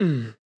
casting.wav